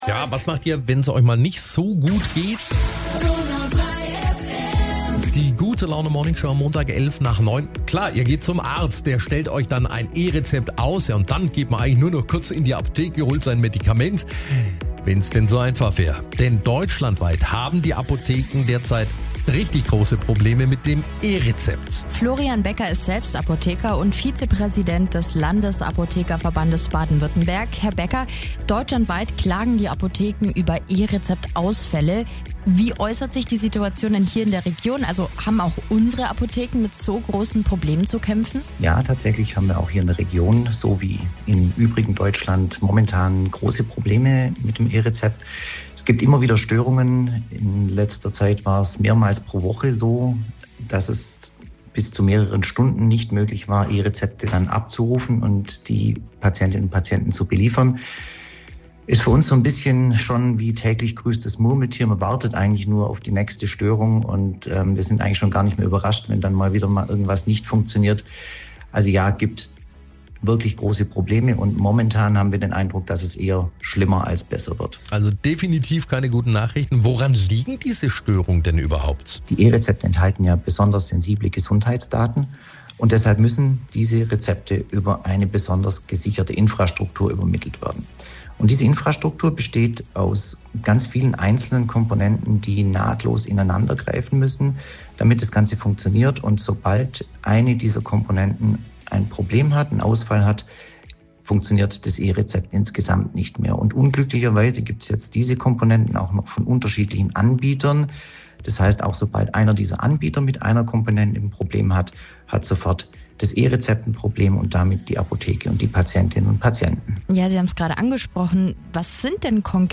Radiobeitrag Donau3 FM 25. Aug. 2025 | Probleme beim E-Rezept